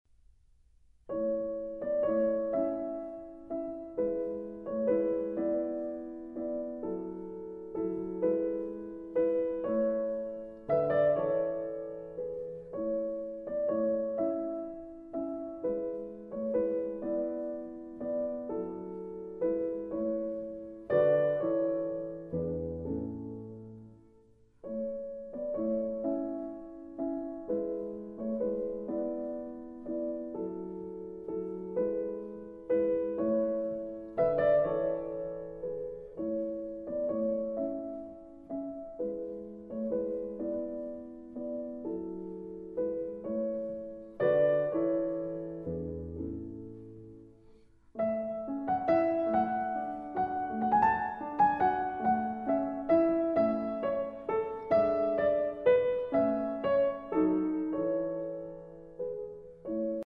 A major